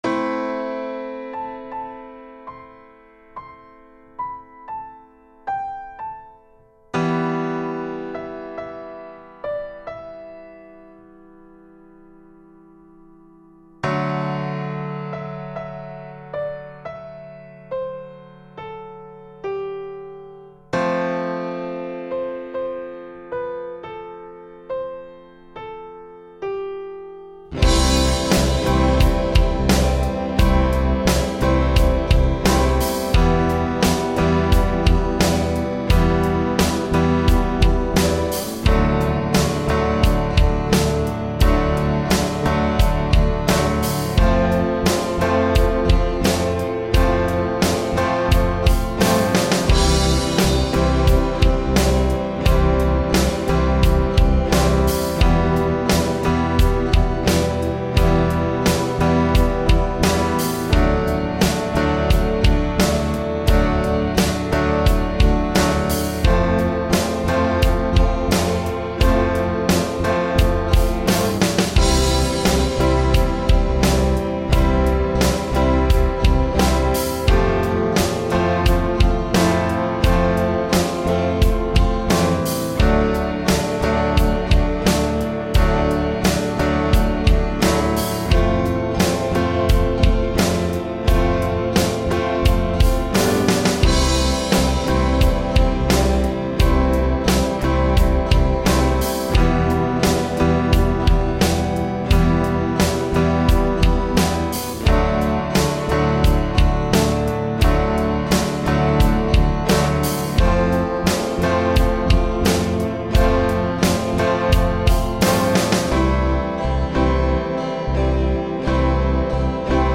Anhänge Instrumental-mp3.mp3 Instrumental-mp3.mp3 2,5 MB · Aufrufe: 312